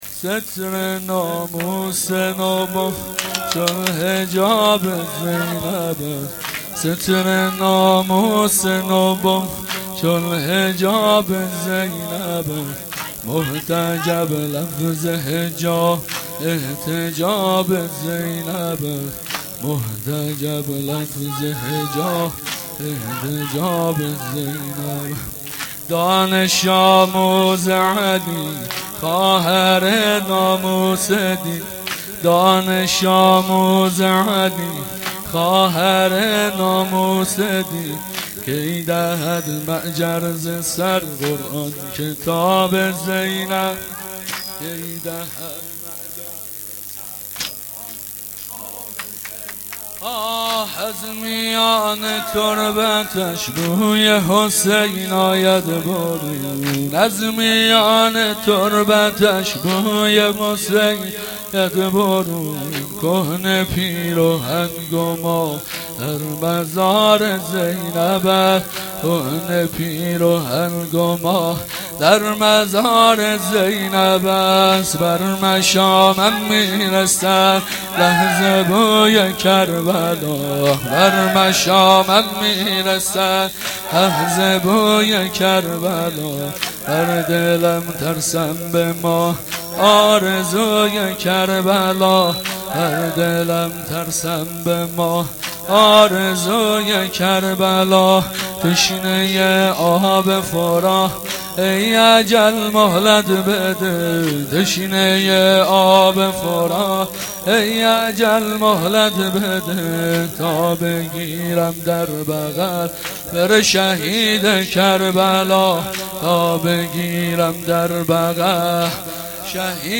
vahed-Rozatol-Abbas.shahadat-emam-Bagher.mp3